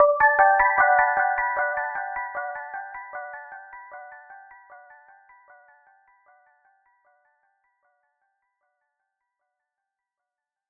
Echoes_Gmaj.wav